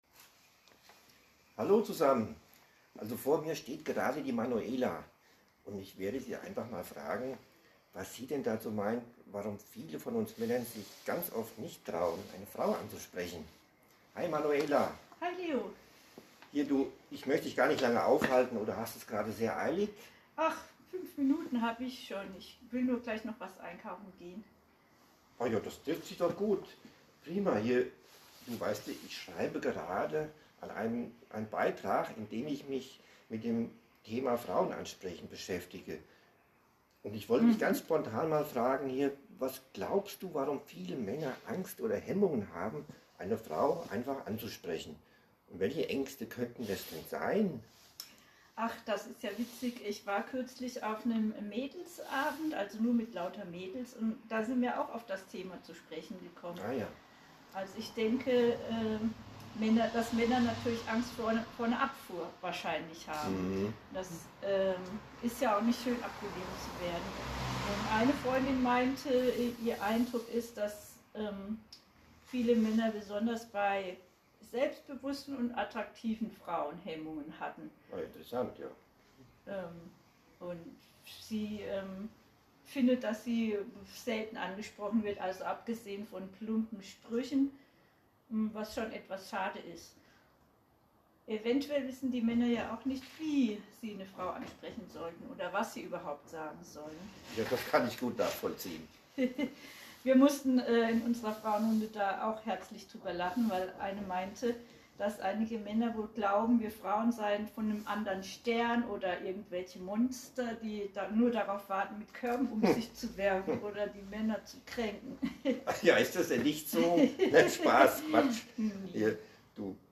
Zum Thema Ängste Frauen anzusprechen habe ich mal eine Frau interviewt.